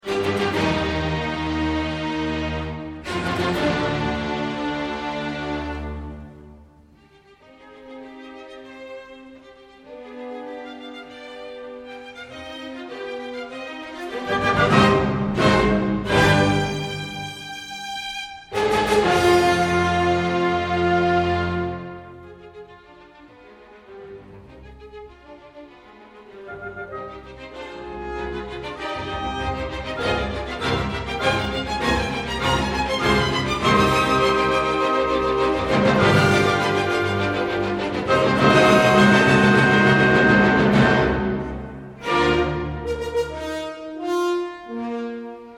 Категория: Классические рингтоны